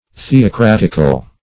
Search Result for " theocratical" : The Collaborative International Dictionary of English v.0.48: Theocratic \The`o*crat"ic\, Theocratical \The`o*crat"ic*al\, a. [Cf. F. th['e]ocratique.] Of or pertaining to a theocracy; administred by the immediate direction of God; as, the theocratical state of the Israelites.